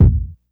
Kicks
Medicated Kick 29.wav